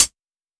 TC3Hat11.wav